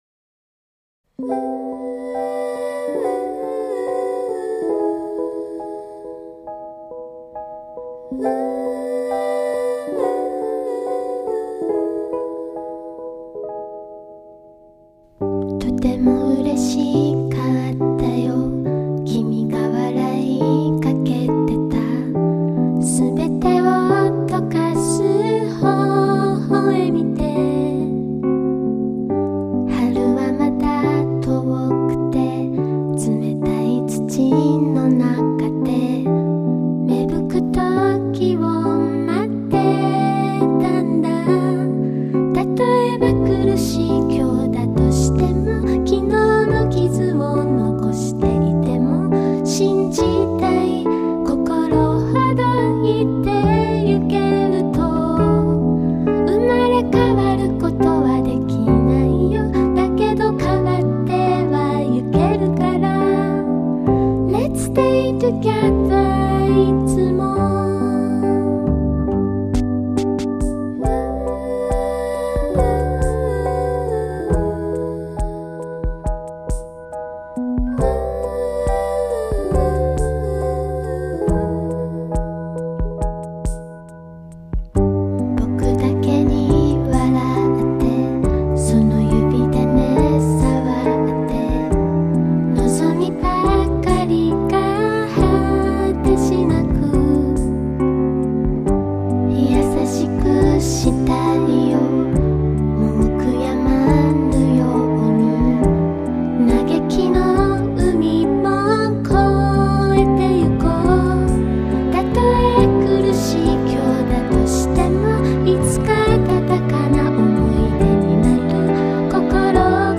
一首很温柔的歌，我想对于很多人来说并不陌生。
这首歌无论是旋律还是歌词，都给人舒服的感觉。